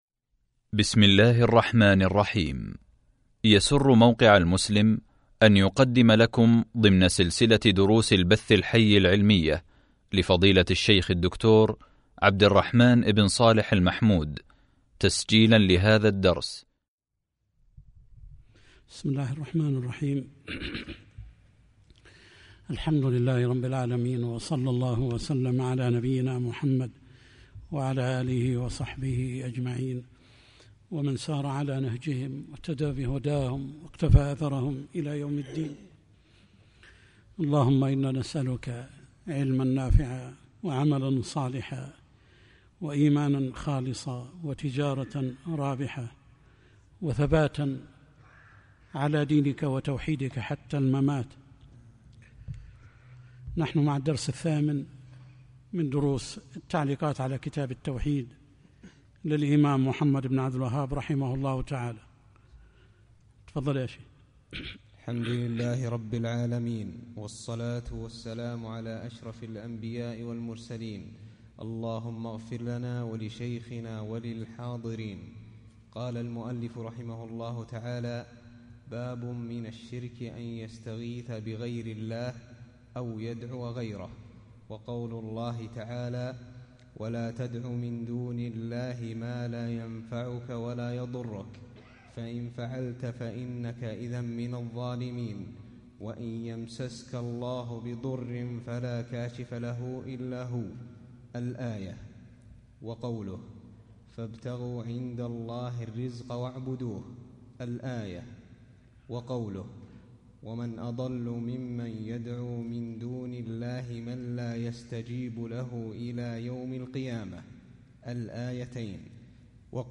شرح كتاب التوحيد | الدرس 8 | موقع المسلم